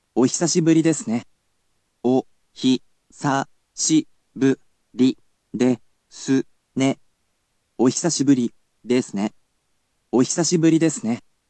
You’ll be able to hear an organic voice in another resource, but for now, he’ll definitely help you learn whilst at least hearing the words and learning to pronounce them.